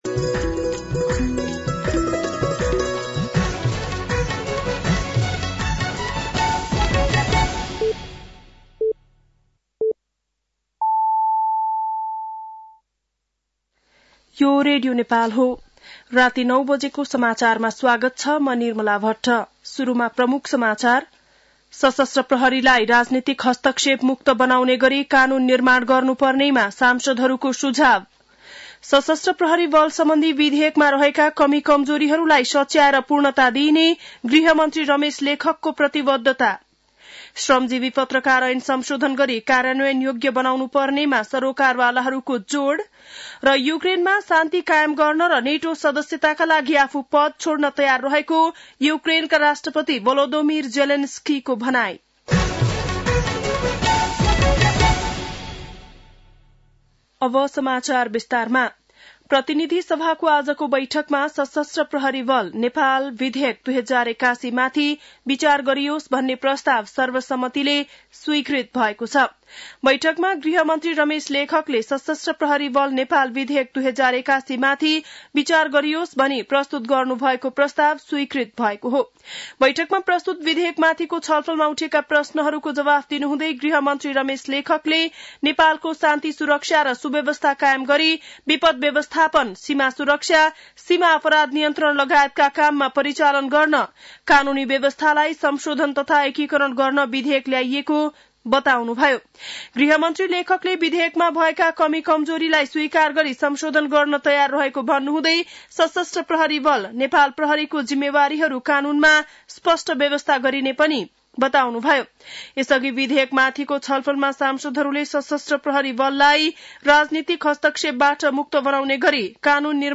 बेलुकी ९ बजेको नेपाली समाचार : १३ फागुन , २०८१
9-PM-Nepali-NEWS-11-12.mp3